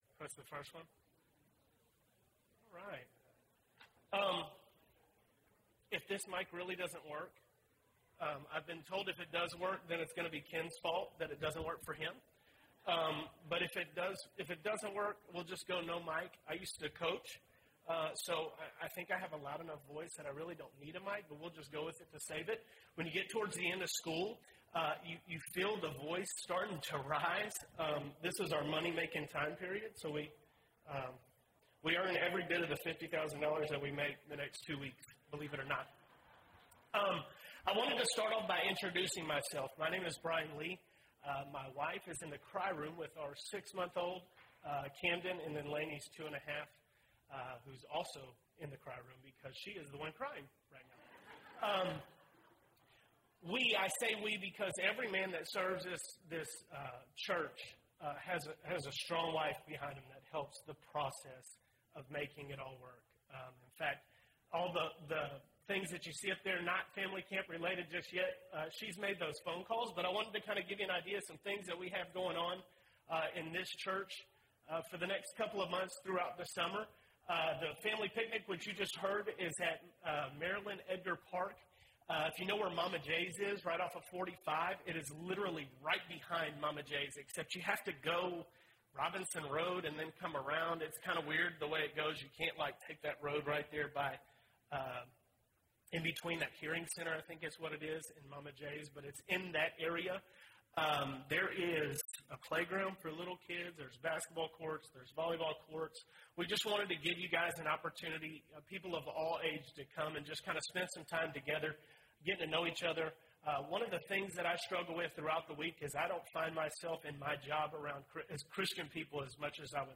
Commended for Faith (9 of 10) – Bible Lesson Recording